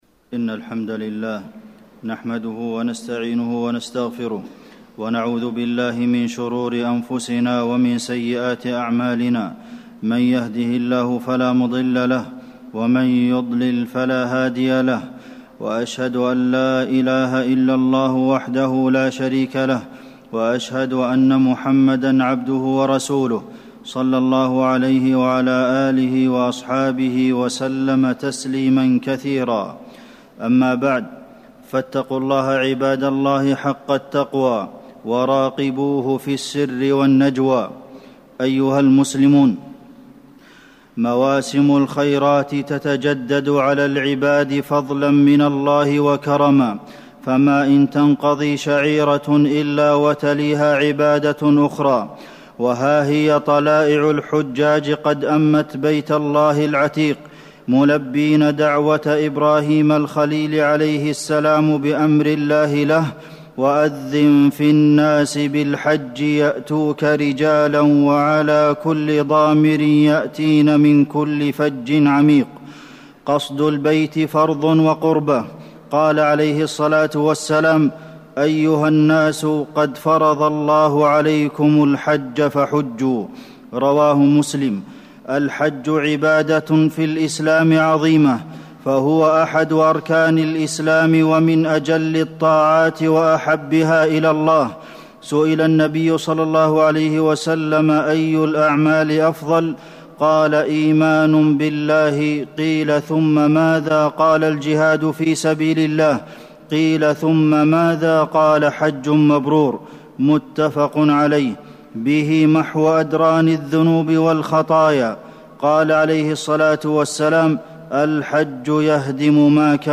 تاريخ النشر ٢٨ ذو القعدة ١٤٣٩ هـ المكان: المسجد النبوي الشيخ: فضيلة الشيخ د. عبدالمحسن بن محمد القاسم فضيلة الشيخ د. عبدالمحسن بن محمد القاسم فضل الحج وأيام العشر